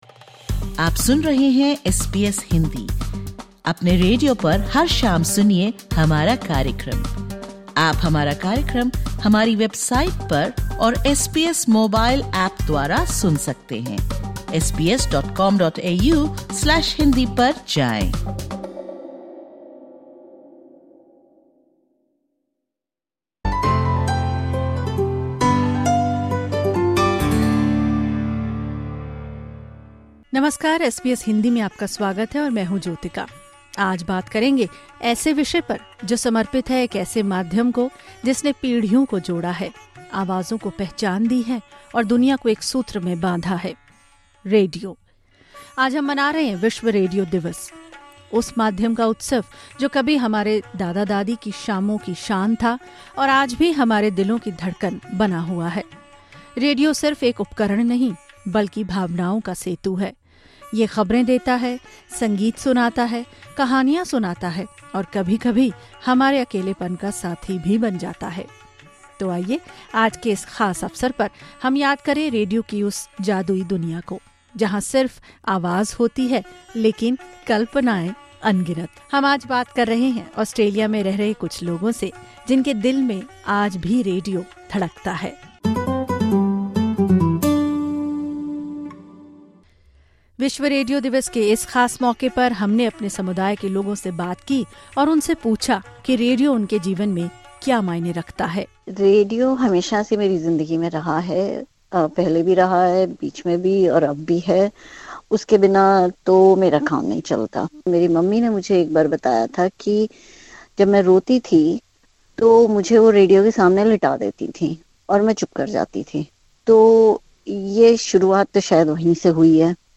People from community shared their memories with listening to radio.
Disclaimer: The views expressed in this podcast are that of the interviewees.